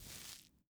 Grabbing_01.wav